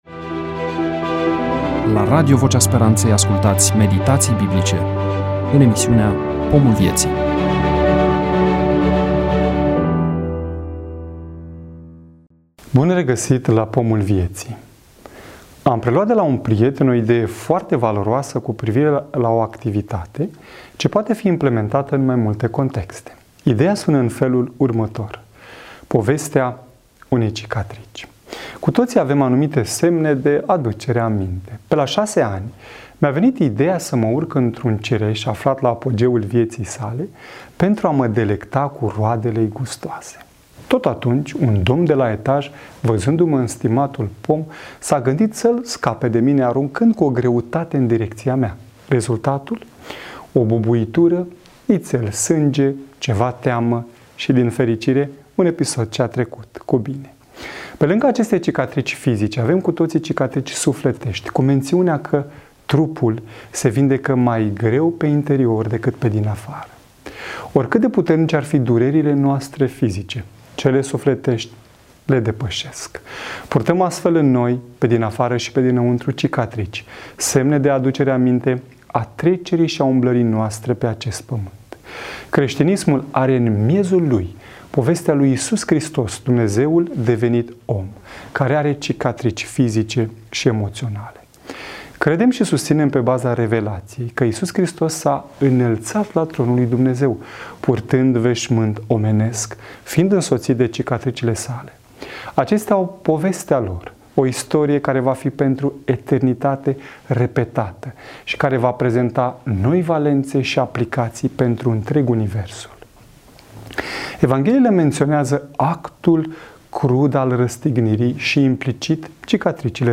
EMISIUNEA: Predică DATA INREGISTRARII: 18.09.2024 VIZUALIZARI: 62